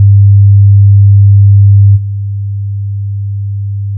《基準壁》からの音 2秒 →《基準壁+ノイズクリア》からの音 2秒
- 固体伝播音の場合 -
( スピーカーを壁に直付け固定して測定 )